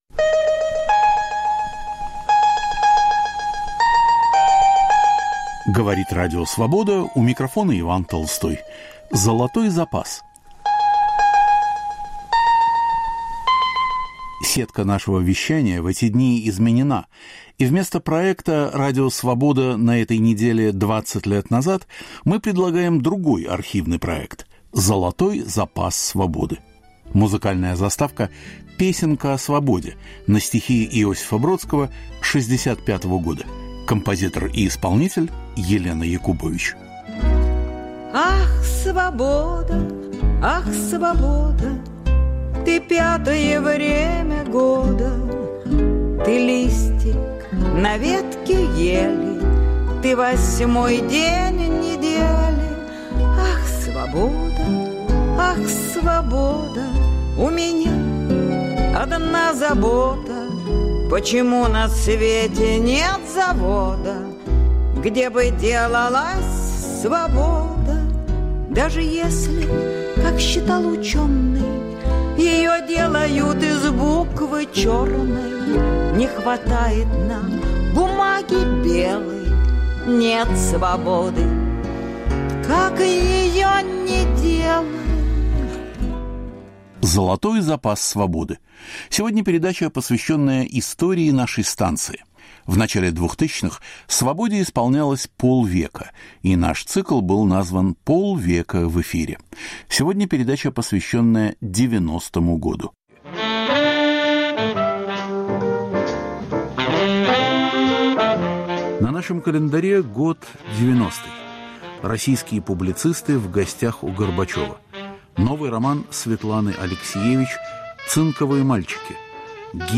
К 50-летию Радио Свобода. 1990, архивные передачи: роман Светланы Алексиевич "Цинковые мальчики". Гибель Виктора Цоя. Юлий Ким, Булат Окуджава, Юнна Мориц выступают перед микрофоном Свободы.